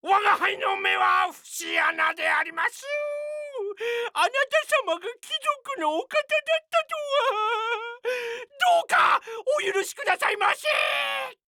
CV ：子安武人